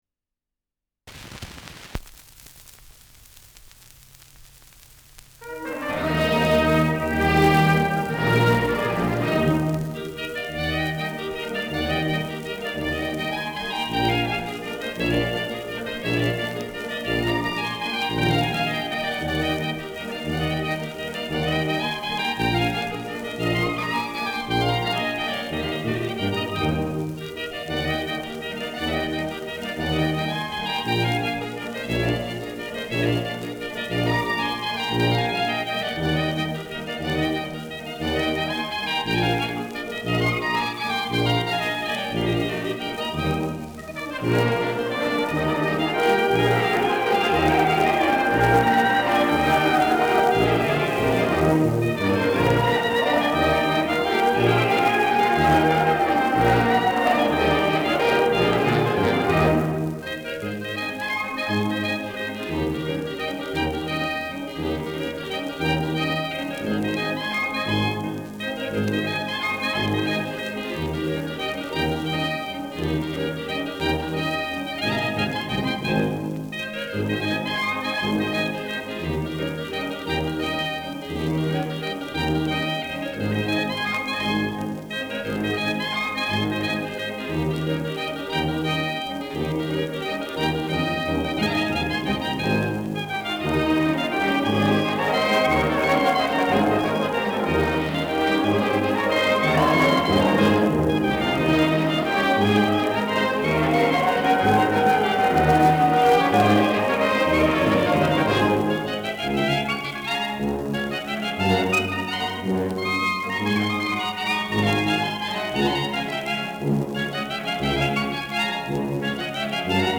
Schellackplatte
Leicht abgespielt : Vereinzelt leichtes Knacken
[Berlin] (Aufnahmeort)